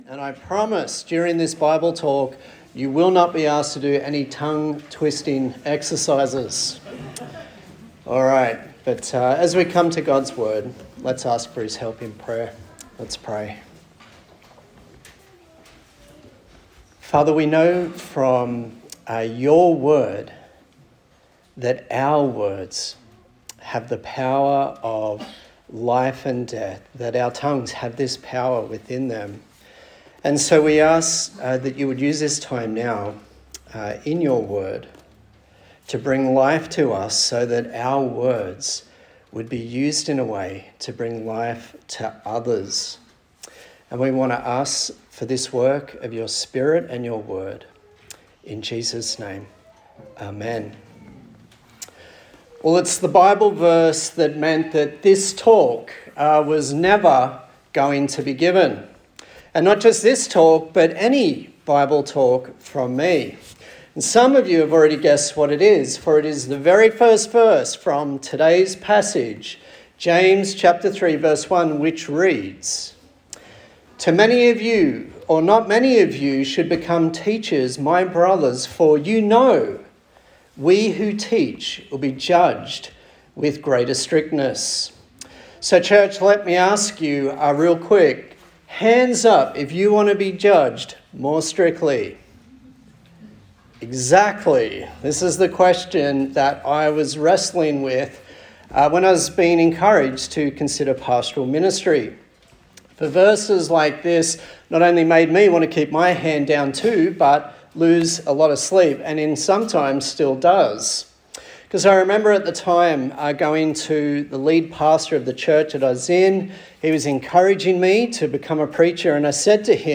A sermon in the series on the book of James
Service Type: Morning Service